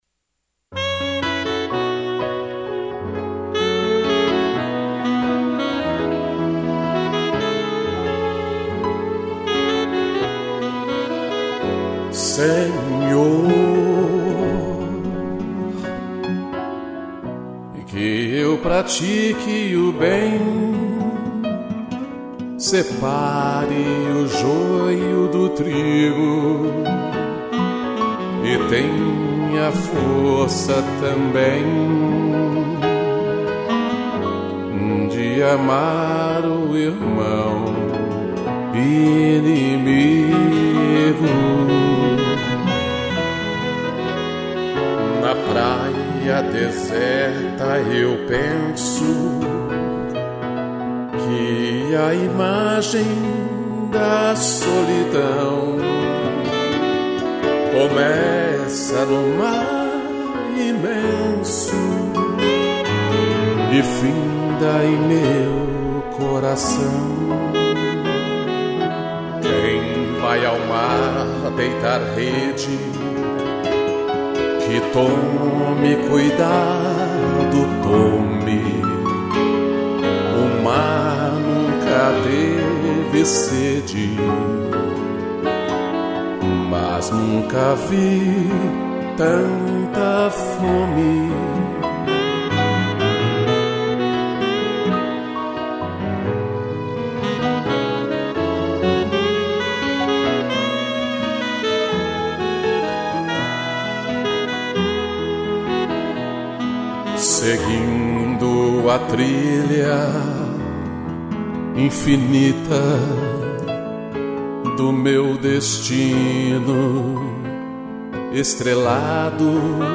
Voz
2 pianos, sax e tutti